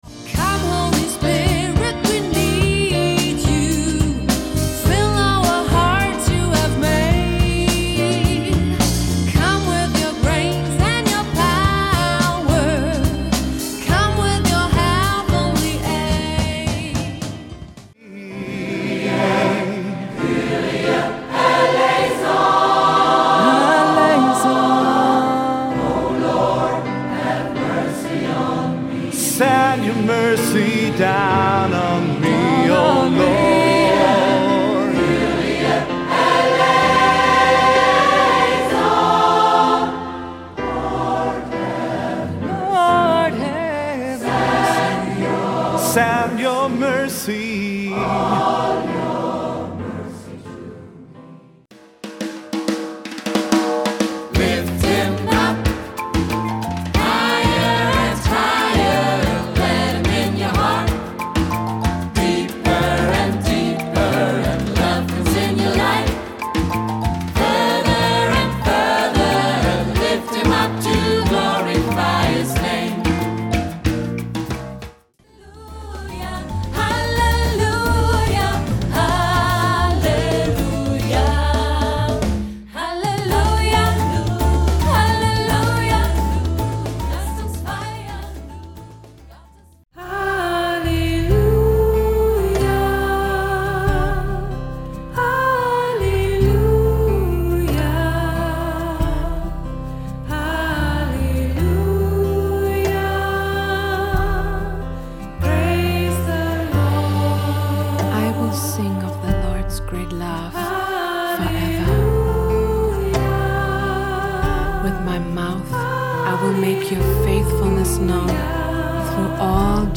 Gospel liturgisch.